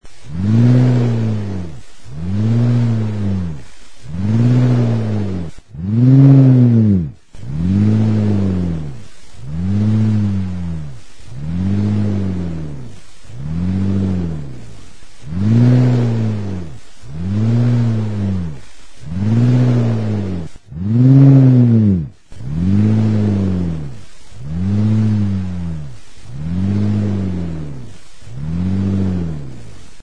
FIRRINGILA | Soinuenea Herri Musikaren Txokoa
Zurezko ohol bat da.
Classification: Aérophones -> Libres